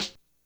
snare02.wav